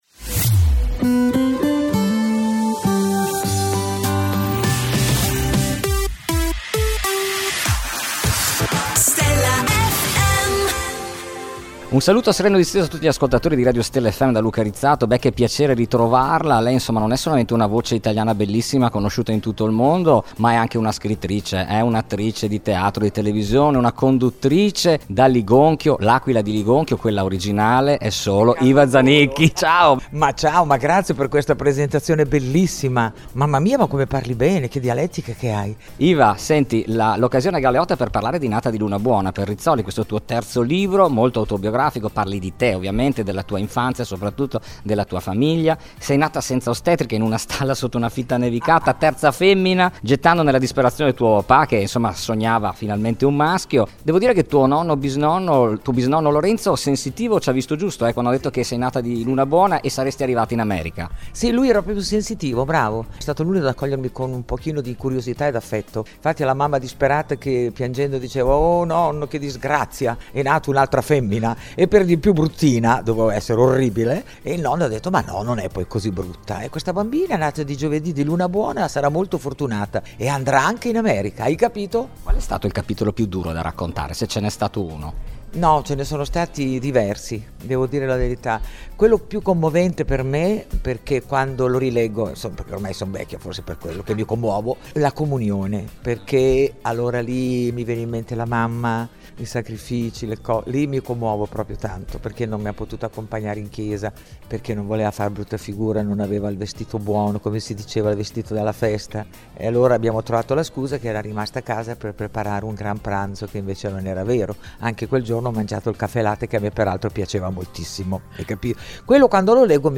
Intervista I Iva Zanicchi | Stella FM
Intervista esclusiva dell’inviato per Stella FM a Iva Zanicchi.